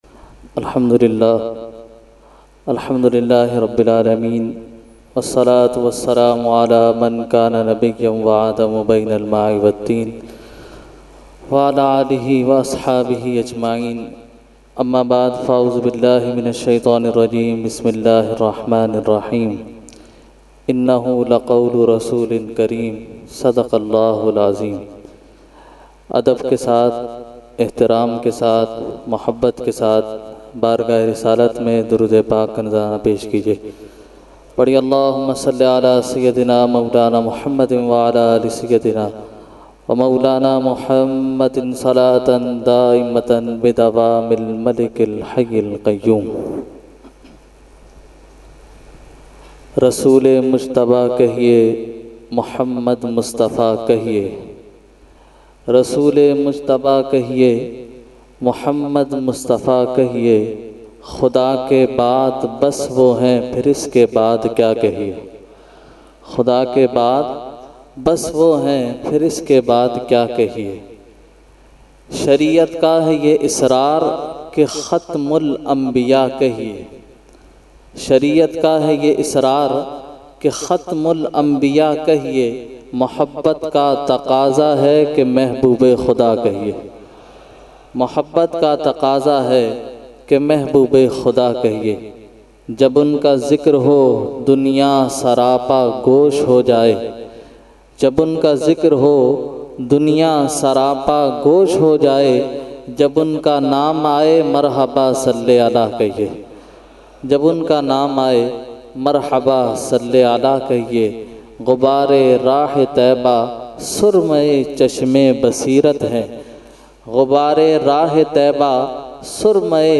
Jashne Subhe Baharan held 29 October 2020 at Dargah Alia Ashrafia Ashrafabad Firdous Colony Gulbahar Karachi.
Category : Speech | Language : UrduEvent : Jashne Subah Baharan 2020